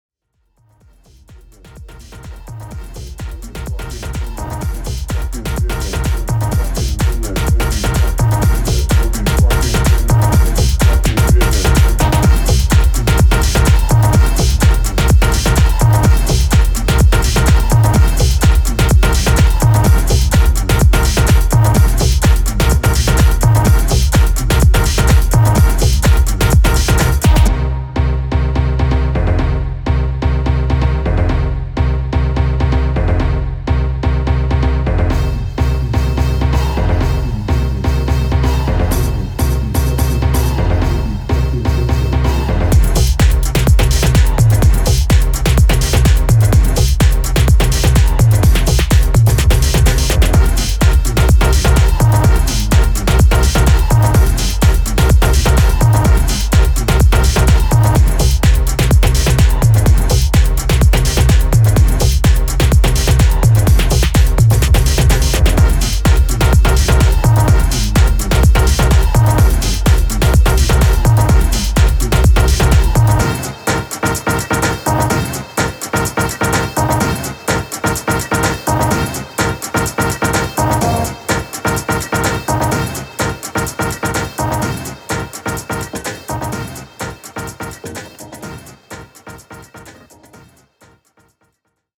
執拗なオーケストラ・ヒットで追撃するブレイクビーツ・ハウス・ジャム